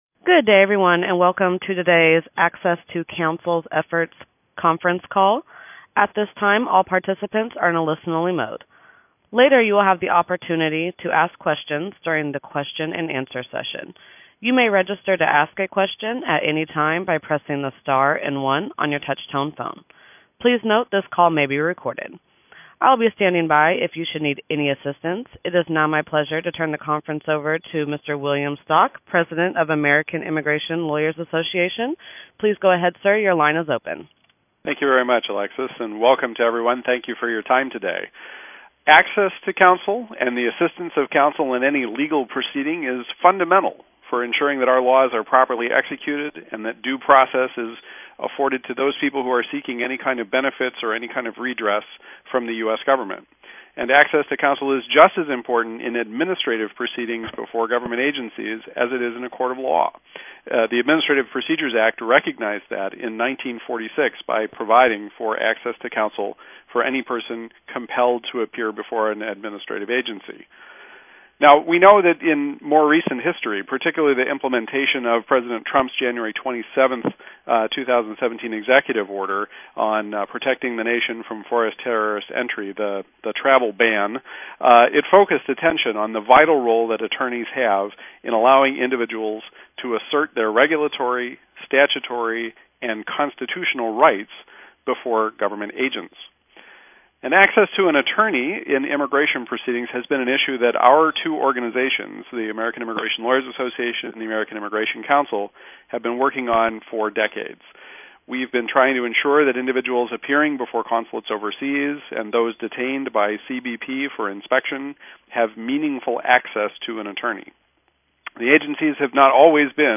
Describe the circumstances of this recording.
On 5/25/17, AILA and the American Immigration Council held a call for reporters to highlight the petition for rulemaking submitted to the federal government to ensure all immigrants have access to legal counsel in secondary and deferred inspection, as well as overseas consular interviews.